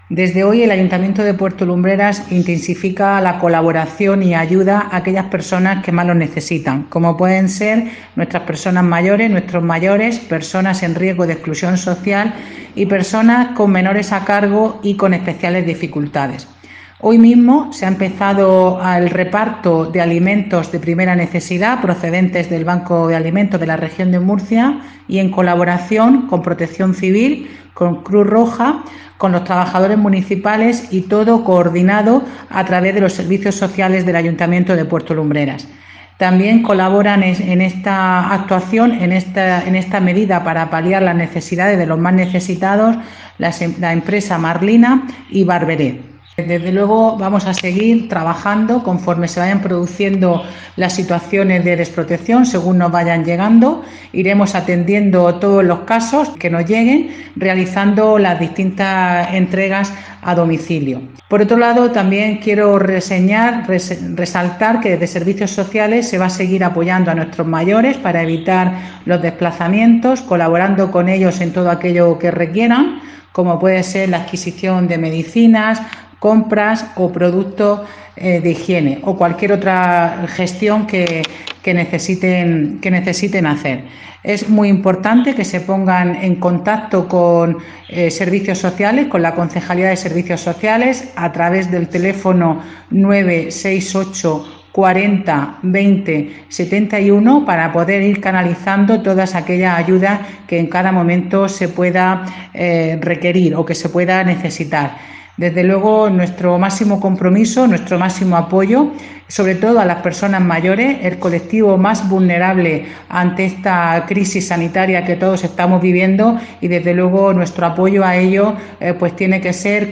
María-Ángeles-Túnez-alcaldesa-de-Puerto-Lumbreras-1.mp3